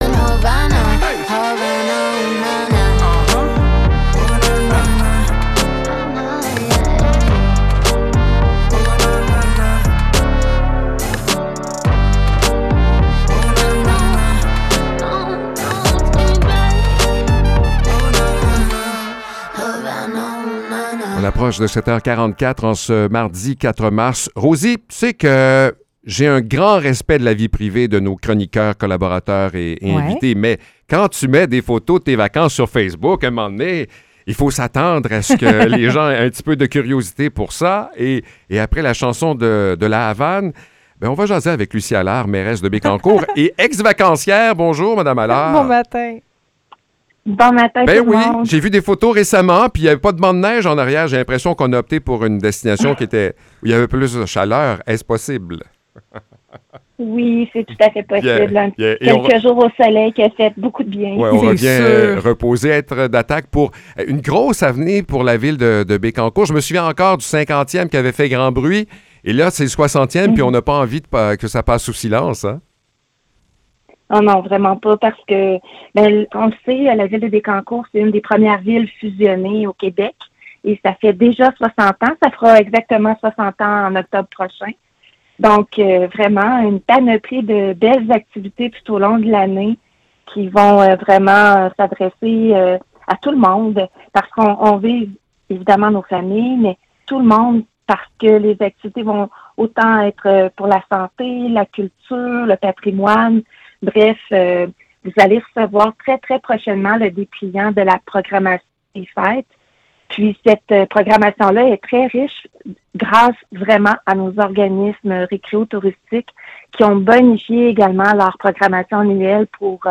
Échange avec Lucie Allard